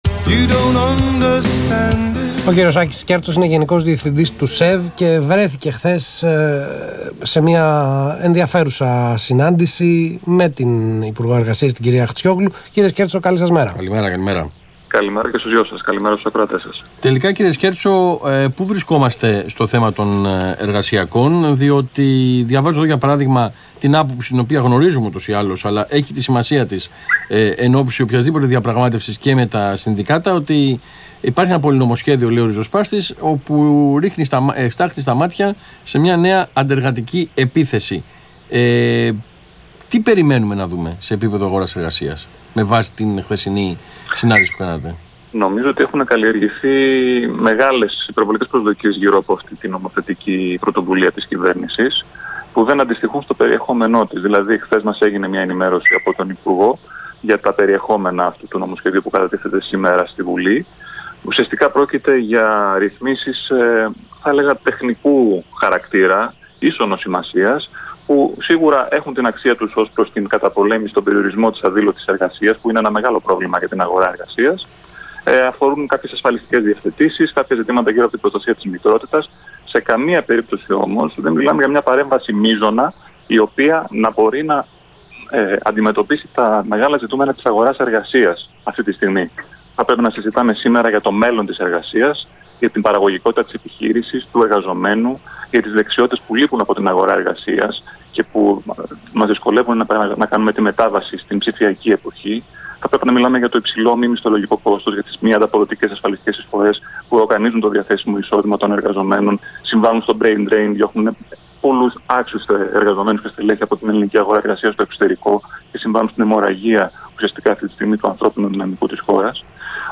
Ραδιοφωνική παρέμβαση του Γενικού Διευθυντή του ΣΕΒ, κ. Άκη Σκέρτσου στον Ρ/Σ Αθήνα 9.84 για το ν/σ του υπουργείου εργασίας, 29/8/2017